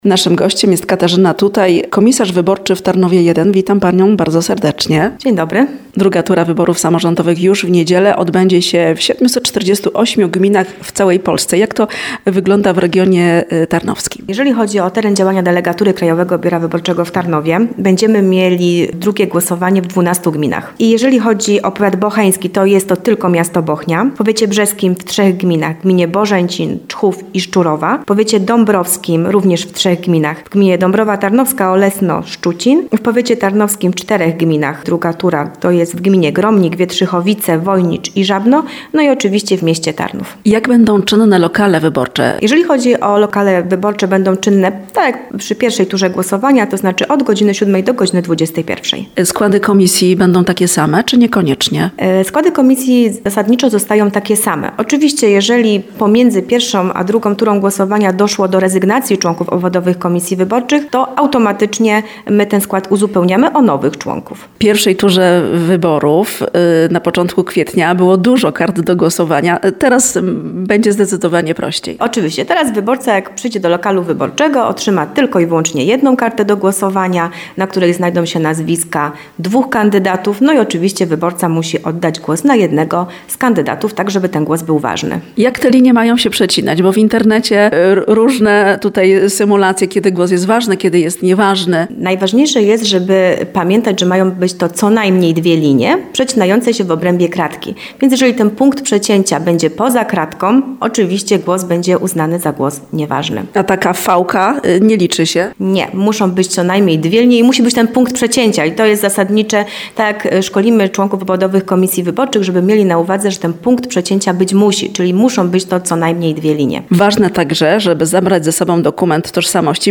– mówi komisarz wyborczy Tarnów I Katarzyna Tutaj.
18wywiad_wybory.mp3